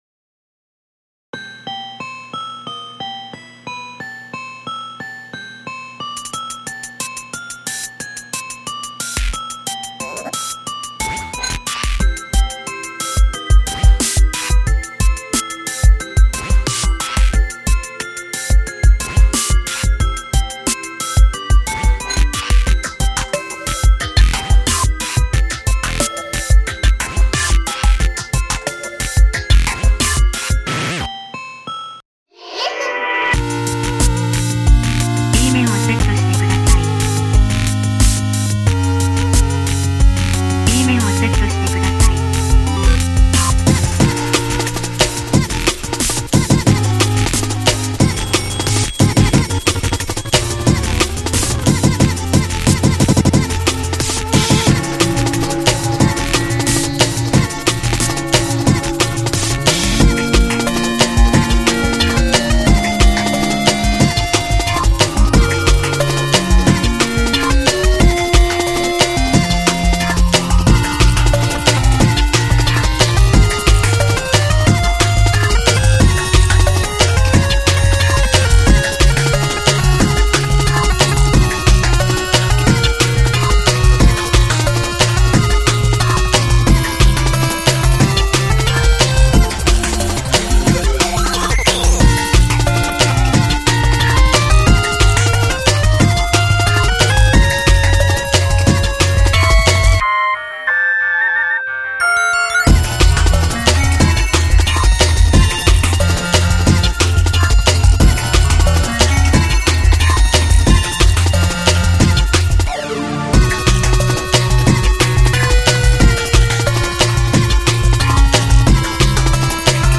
以前テクノとしてアレンジし
Mac speak text kyoko
YMCK Magical 8bit Plug
Plogue chipspeech